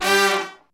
G3 POP FAL.wav